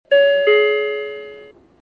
日立独特２点チャイムです。　ドアの閉まり方が「ガラガラ」うるさいですね。